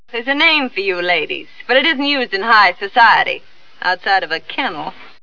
Hard as nails throughout, she uses her velvet voice to great effect, and her parting salvo at the end is a killer...
• Joan Crawford - Chrystal Allen